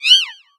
Cri de Chacripan dans Pokémon X et Y.